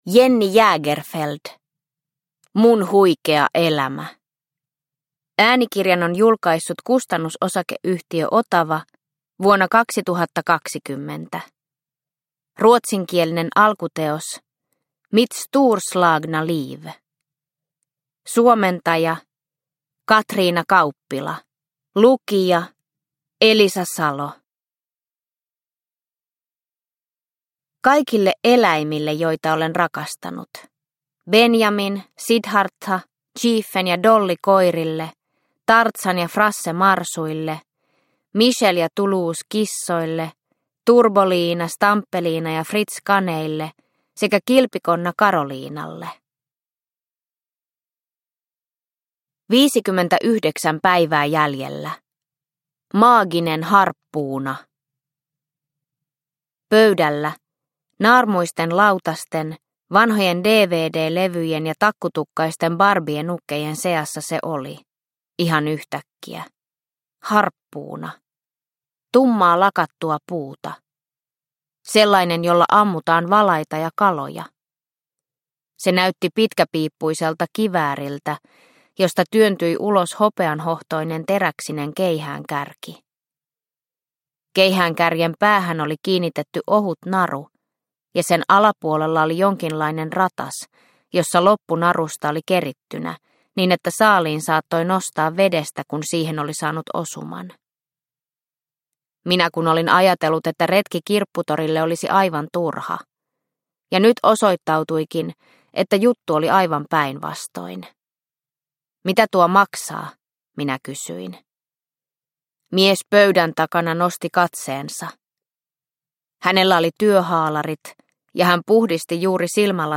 Mun huikea elämä – Ljudbok – Laddas ner